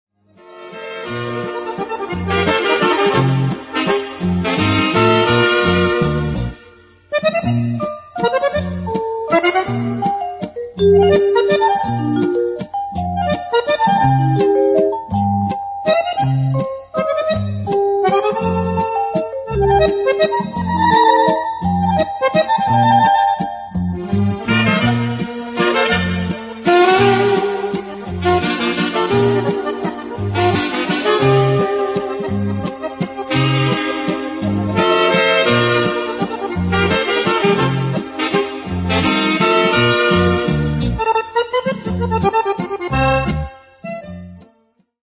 valzer viennese